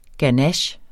Udtale [ gaˈnaɕ ]